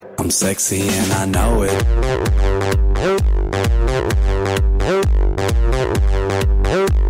high-energy audio clip